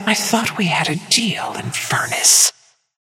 Haze voice line - I thought we had a deal, Infernus.